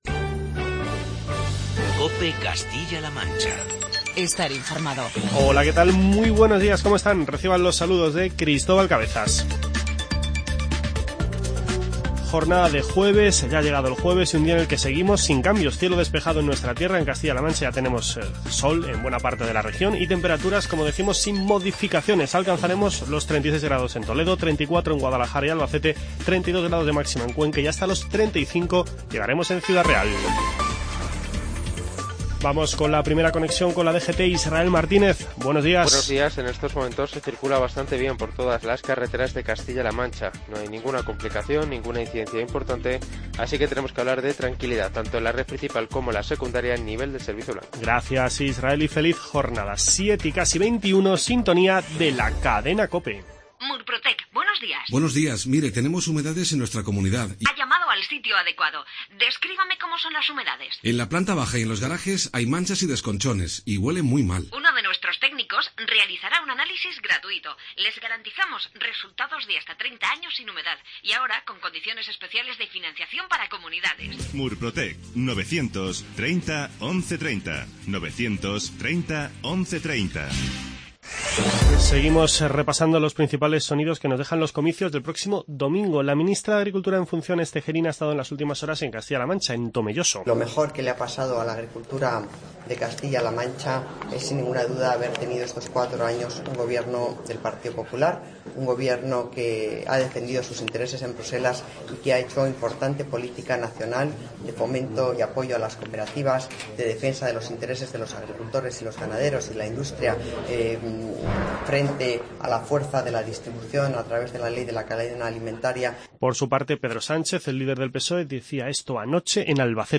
Informativo regional
Repasamos los sonidos más destacados de las últimas horas de campaña electoral.